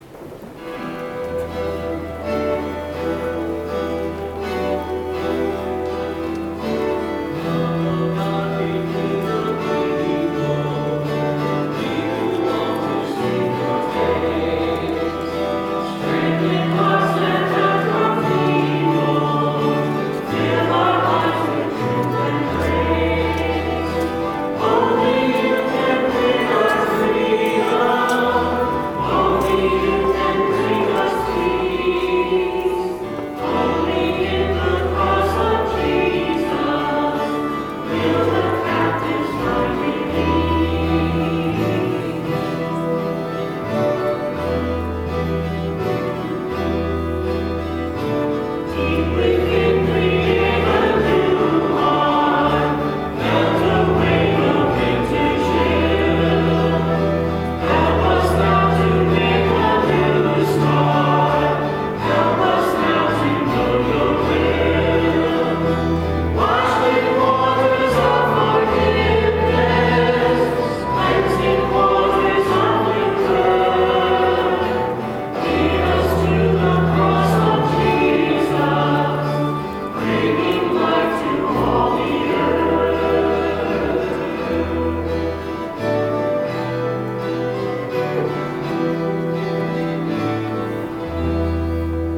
Music from the 10:30 Mass on the 1st Sunday in Lent, March 3, 2013: